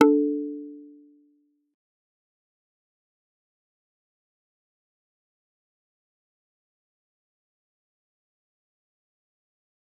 G_Kalimba-D4-mf.wav